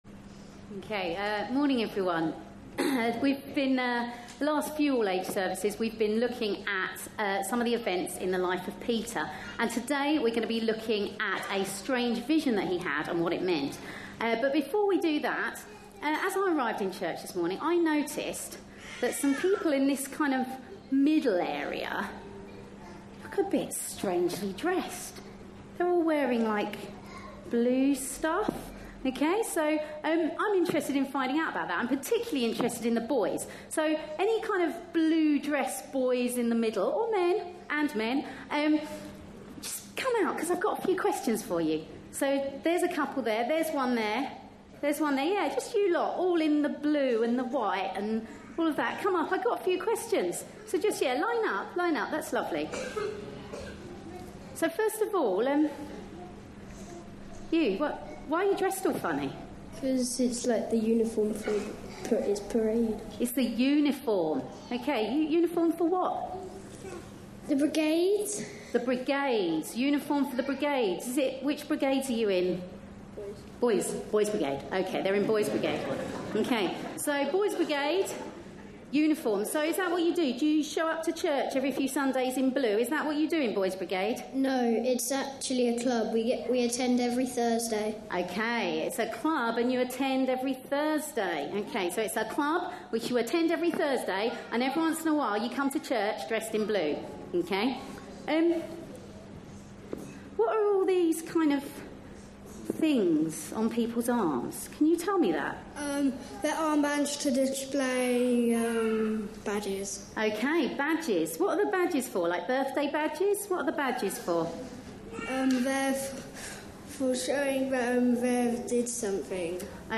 A sermon preached on 19th June, 2016.
This was part of an all-age service for Father's Day, also featuring the Boys' Brigade.